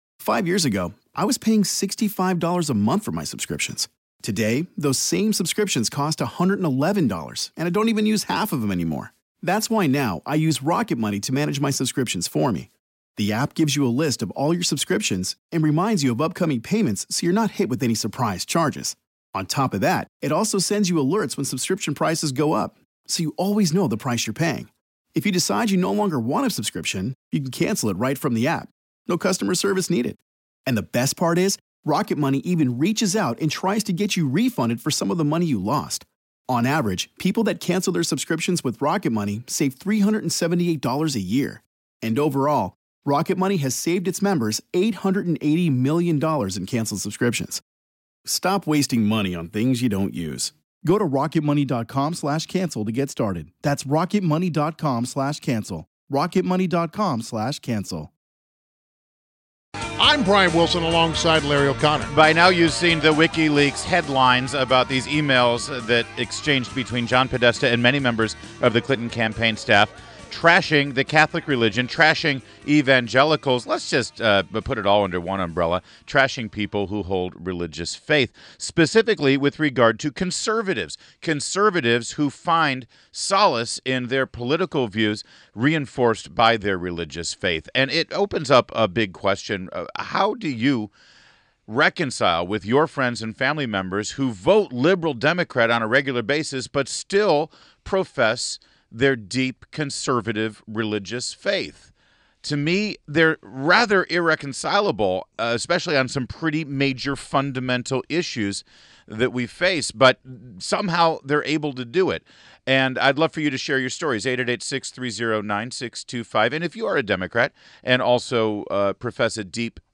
WMAL Interview - RAYMOND ARROYO -10.13.16